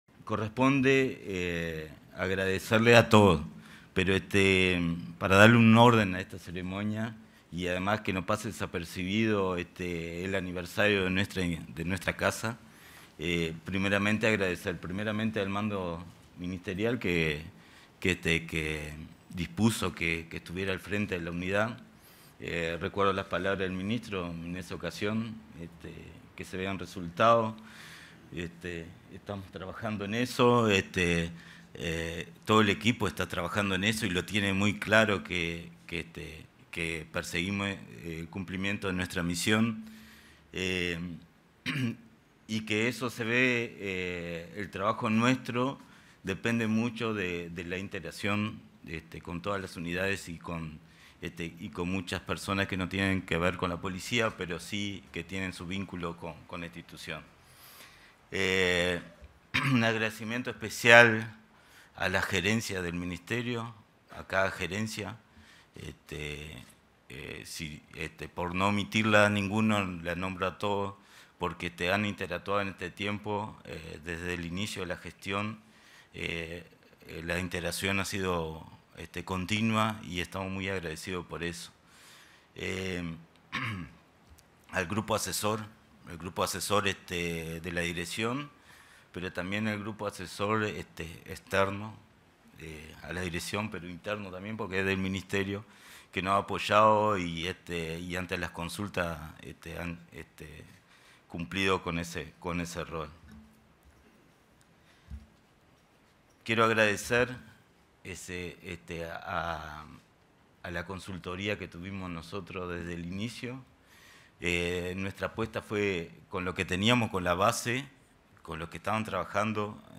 Palabras del director nacional de Asistencia y Seguridad Social Policial
En el marco de la celebración del 48.° aniversario de la Unidad de Asistencia y Seguridad Social Policial y entrega de distintivos a oficiales